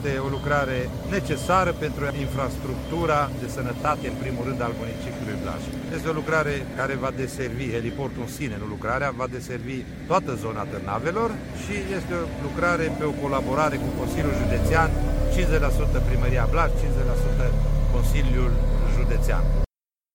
Despre realizarea heliportului a vorbit primarul municipiului Blaj, Gheorghe Valentin Rotar.